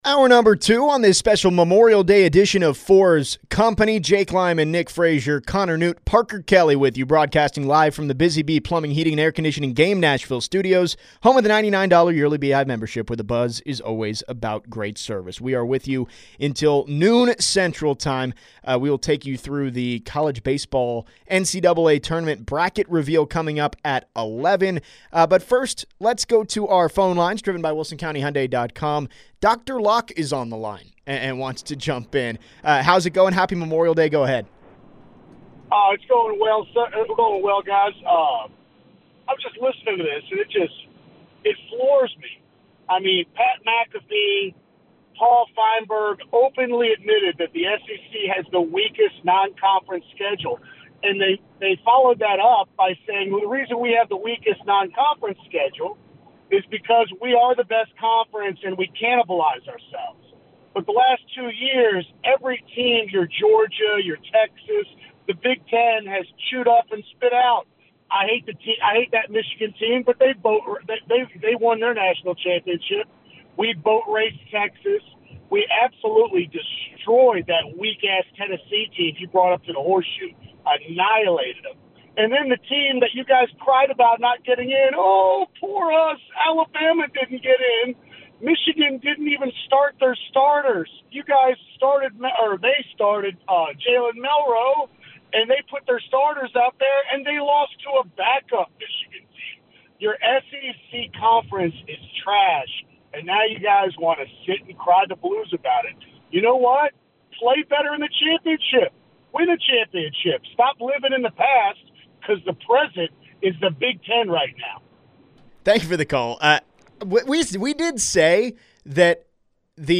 The guys DEBATE should College Football consider the relegation system used in European soccer?